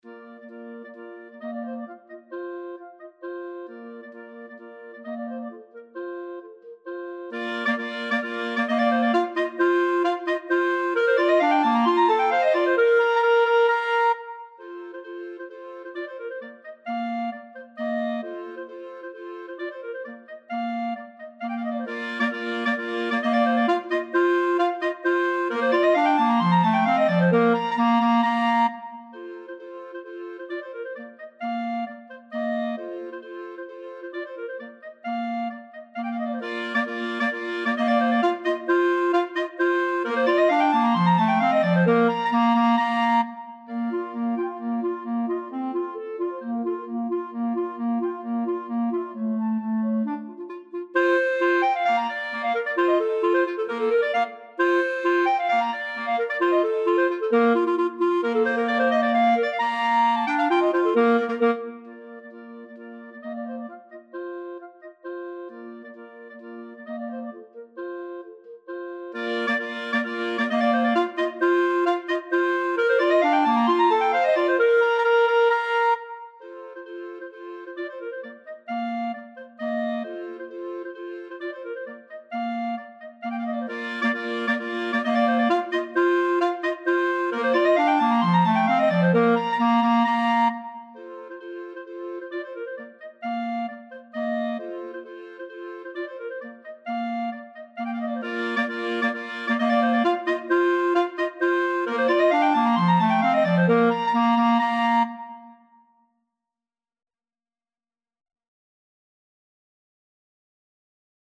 Voicing: Mixed Clarinet Quartet